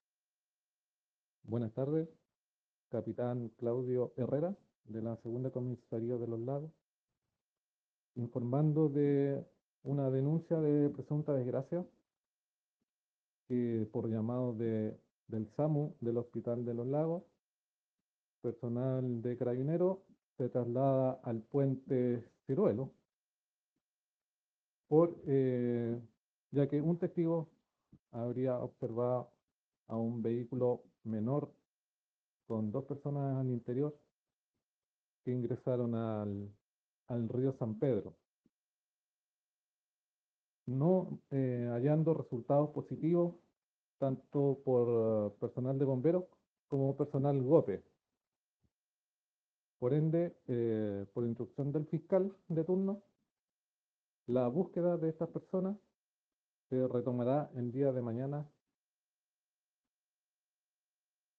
Oficial de Carabineros en en el lugar.